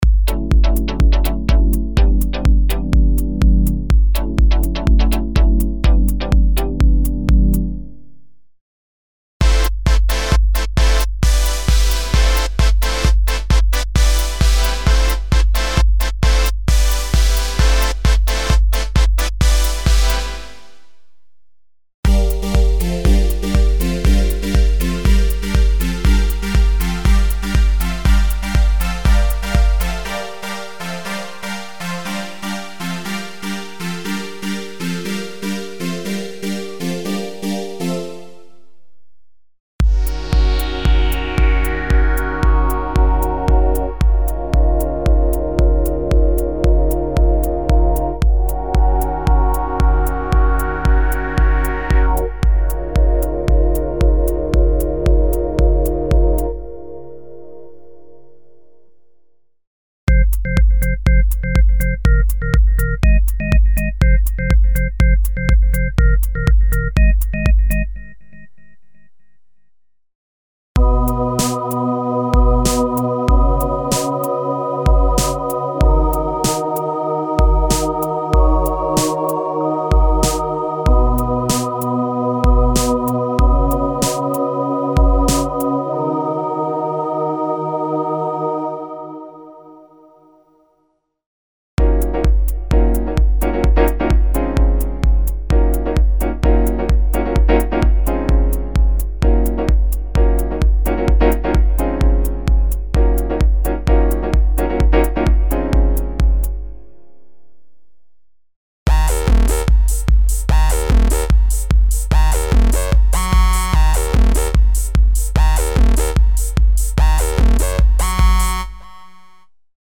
Dance - sound programs for modern dance music styles (filtered and experimental pads, el. strings, synth voices, piano & organ chords and tone intervals, hybrid basses, stacks, etc.).
Info: All original K:Works sound programs use internal Kurzweil K2661 ROM samples exclusively, there are no external samples used.